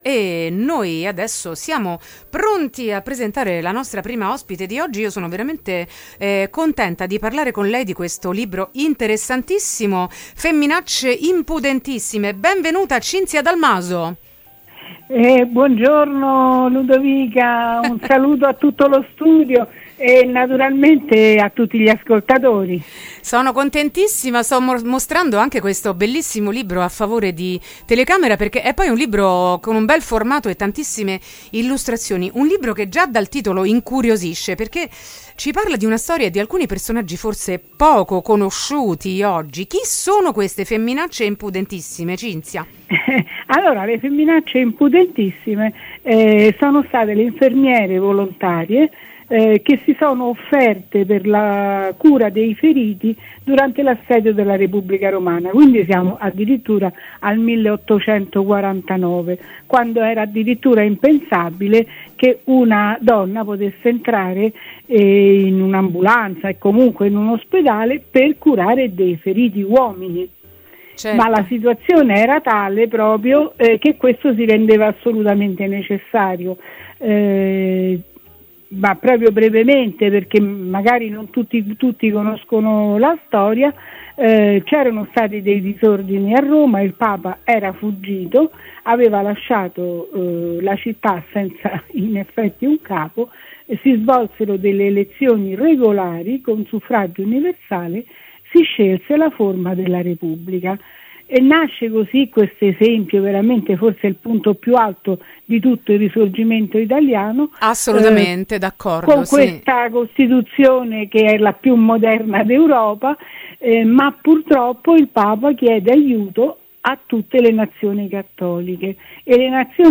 Donne contro i pregiudizi: Intervista